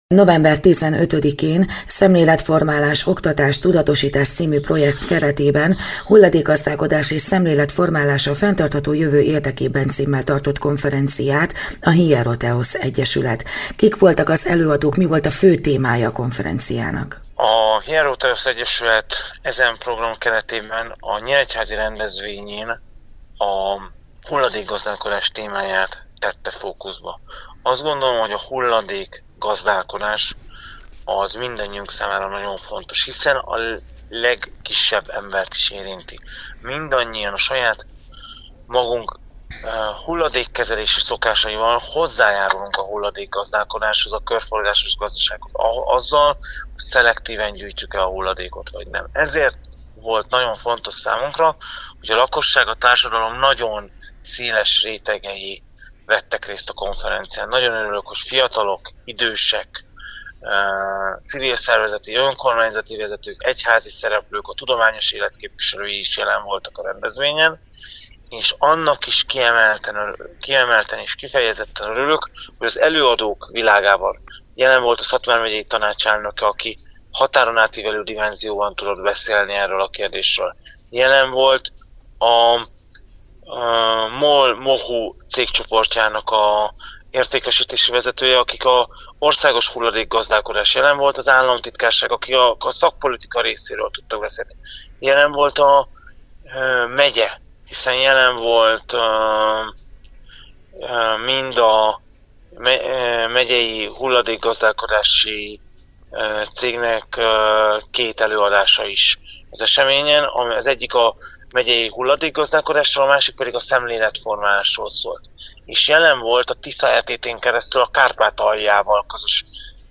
Rádió
2023. november 15.: Nyíregyháza - Hulladékgazdálkodás és szemléletformálás a fenntartható jövő érdekében” című konferenciára - interjú